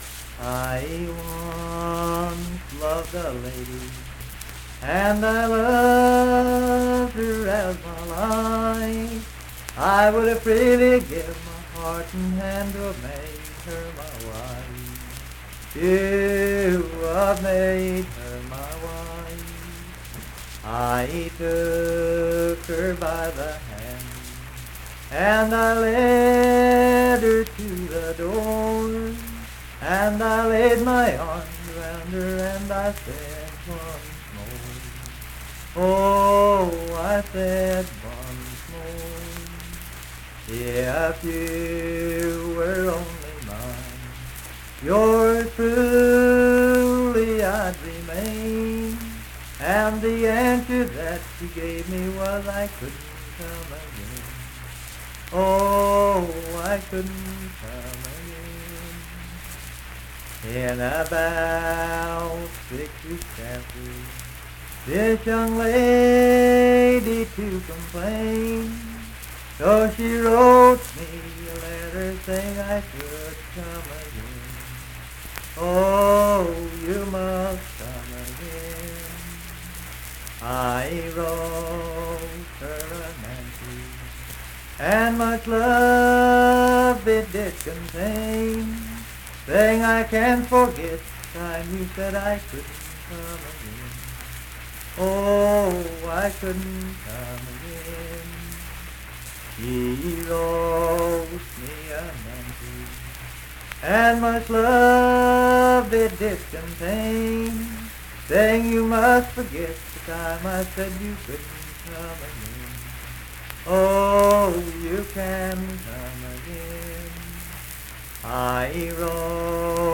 Unaccompanied vocal music performance
Verse-refrain 9(4w/R).
Voice (sung)